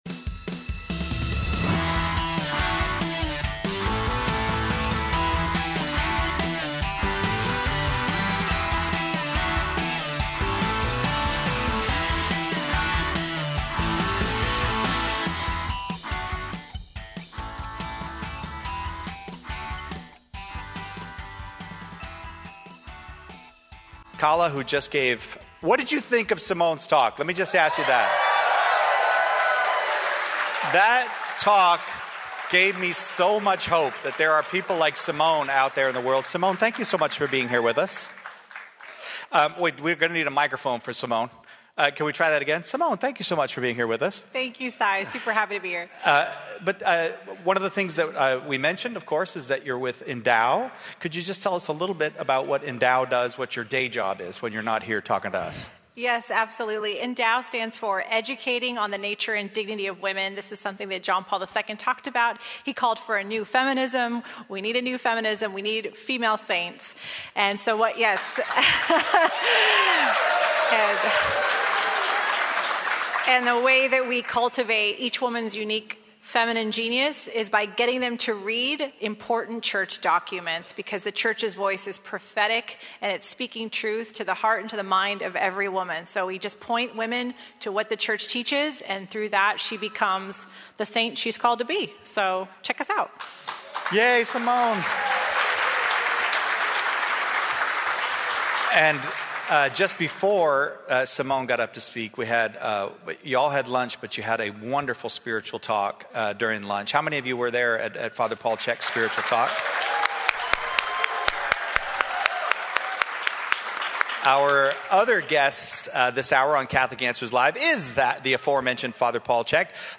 Live from the Catholic Answers Conference